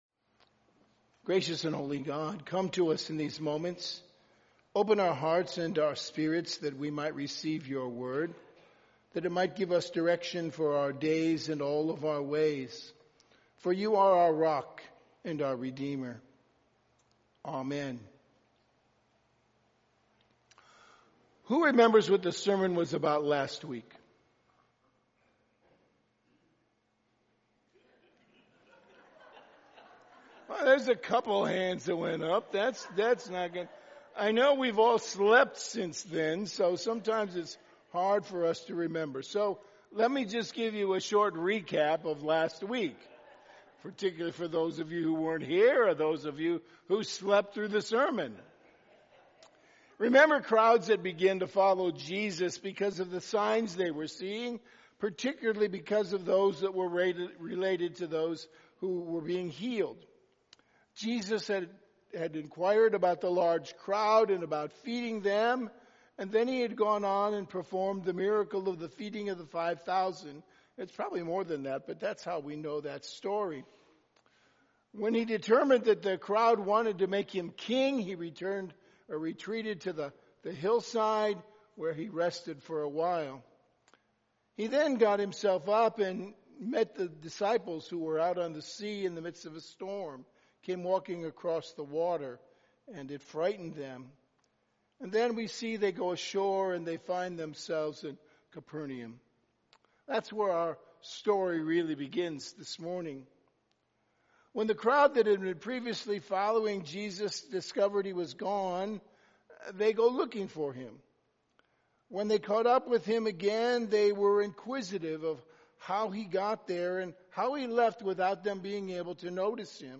Bethlehem UCC Sermons | Page 4 | Bethlehem United Church of Christ
Weekend Worship September 20 & 21, 2025